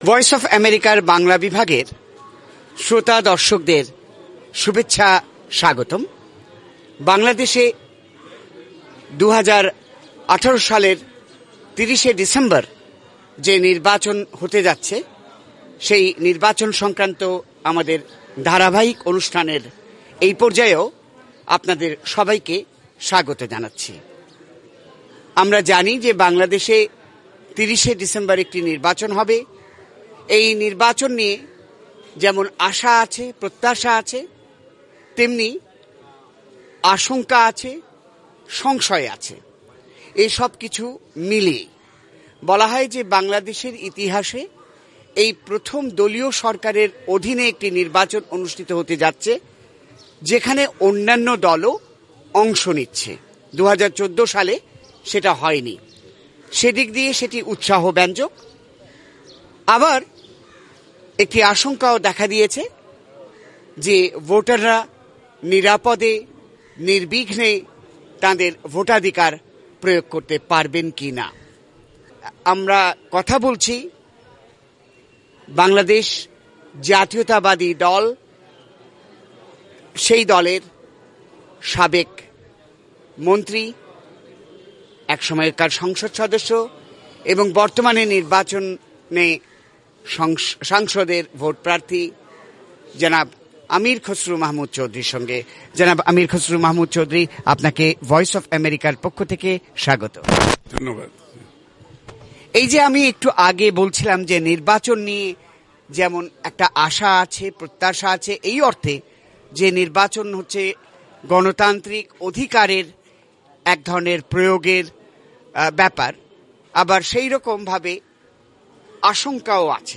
সাক্ষাৎকারে আমীর খসরু মাহমুদ চৌধুরী বলেন, সরকার কারচুপির নির্বাচন করতে চায়। এজন্য ভিন্নমতালম্বীদের উপর নির্যাতন চালাচ্ছে। তিনি অভিযোগ করেন, সেনা নামানোর পরও পরিস্থিতির উন্নতি হয়নি।